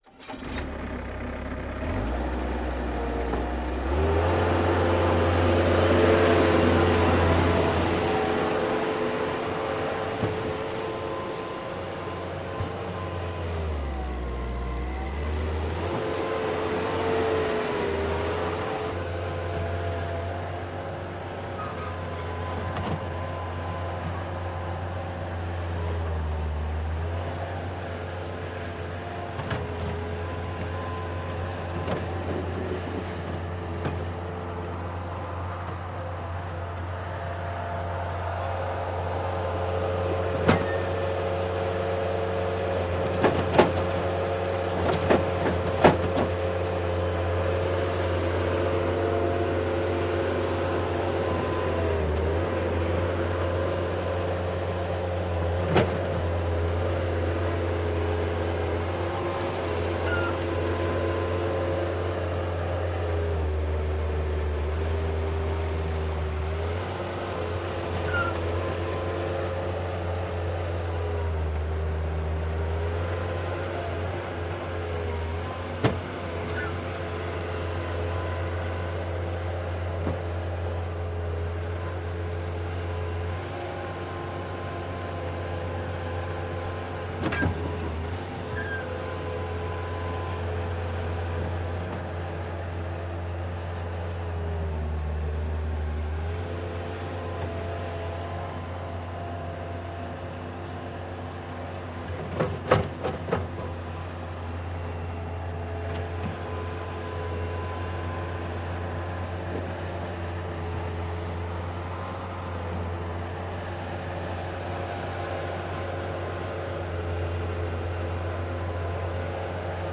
in_bobcat_startgo_01_hpx
Bobcat starts and operates then shuts off. Good panning. Diesel tractor idles. Diesel, Machinery Tractor Engine, Tractor